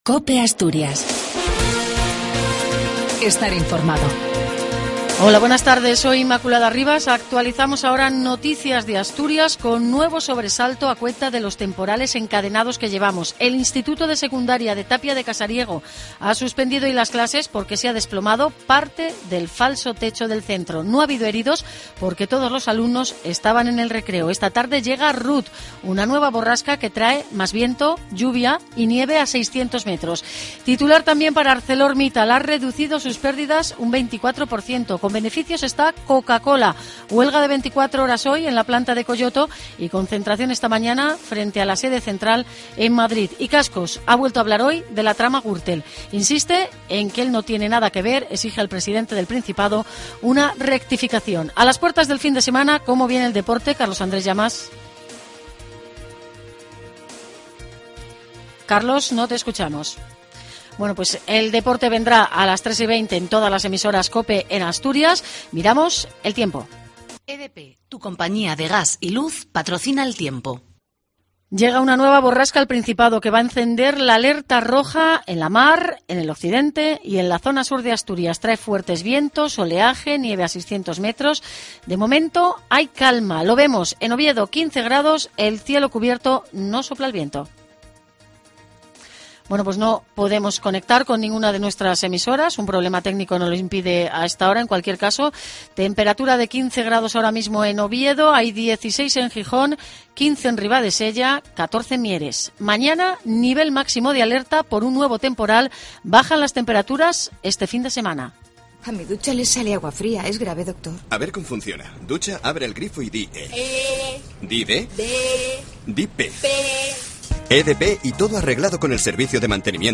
AUDIO: LAS NOTICIAS DE ASTURIAS Y OVIEDO AL MEDIODIA.